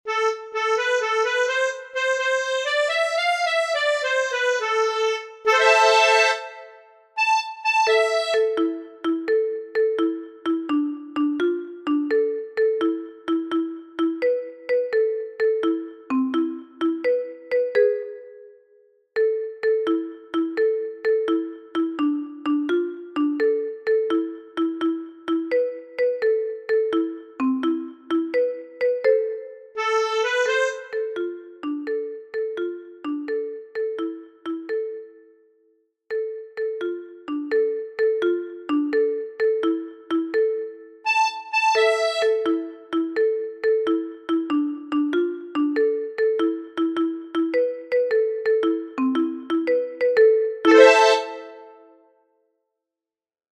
Finally, here you have got the sound file corresponding to the accompaniment.
1._Tarantella_Napoletana_solo_acompanamiento.mp3